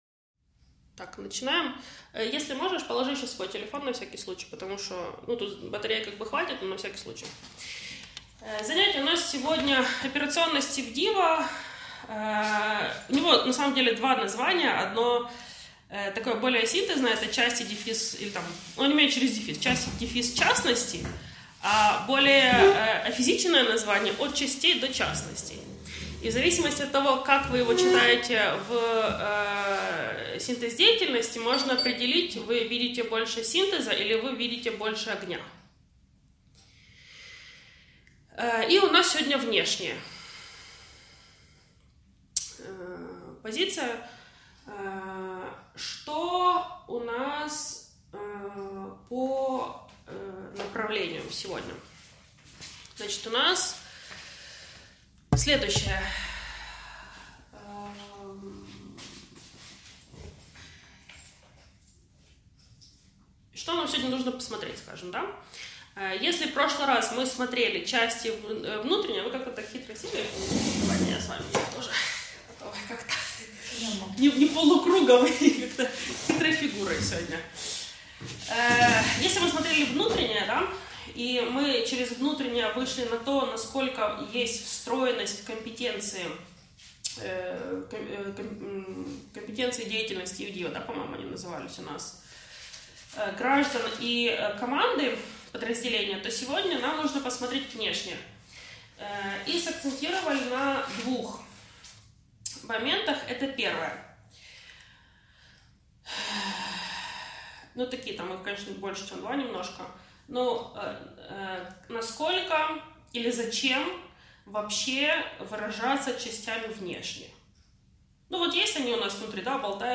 Занятие «Операционность ИВДИВО.